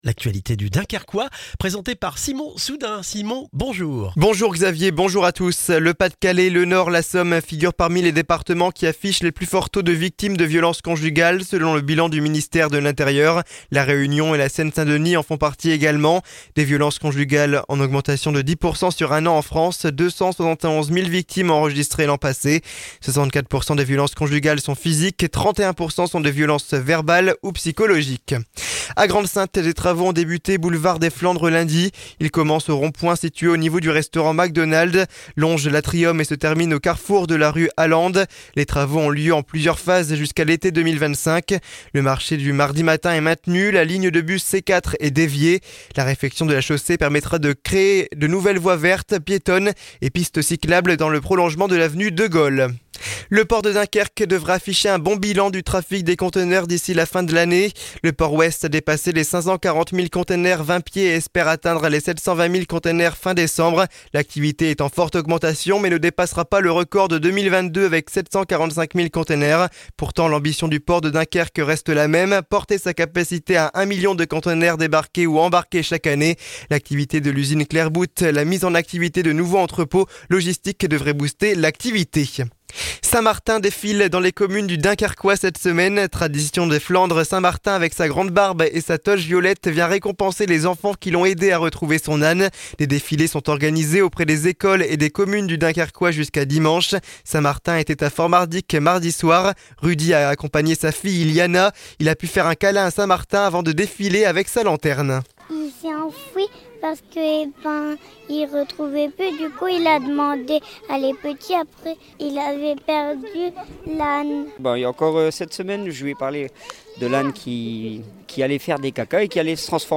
Le journal du jeudi 7 novembre dans le Dunkerquois